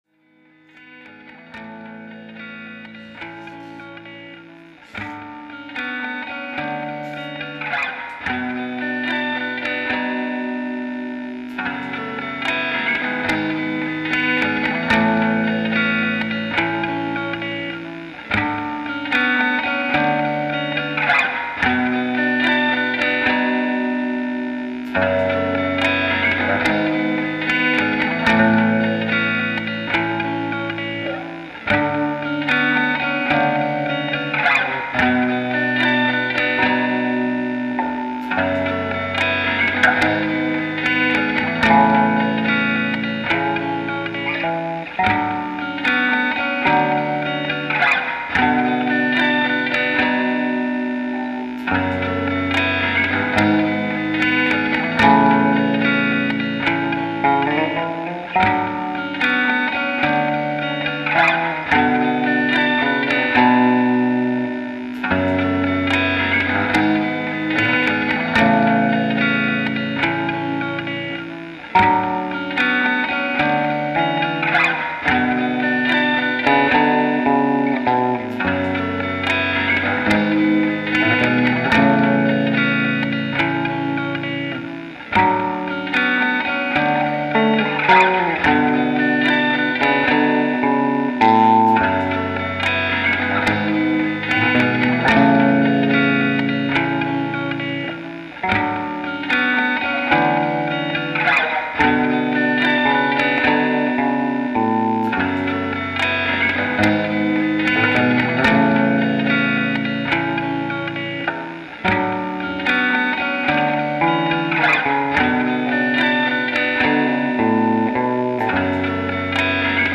handmade (old sketches, demos, errors)